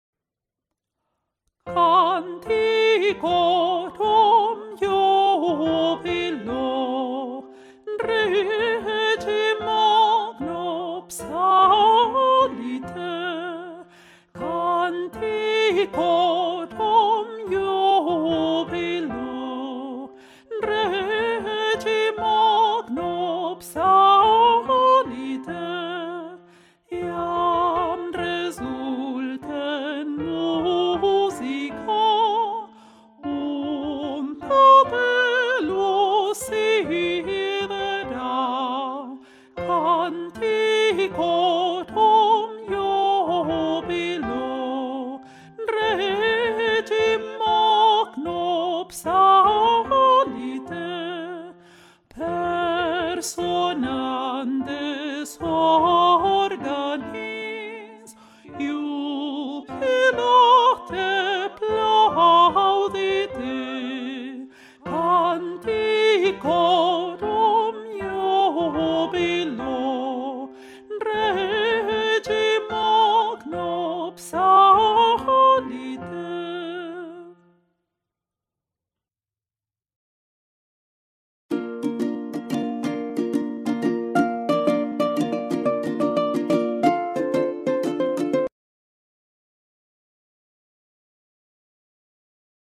mp3 versions chantées
Basse
Bass Rehearsal F Major Bpm 75